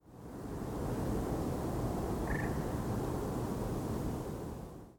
It is also heard at night from stationary birds and may occasionally be heard from flying birds, but seems to be quite rare.
Moorhen krrrk call
clip_mh_krrrk.mp3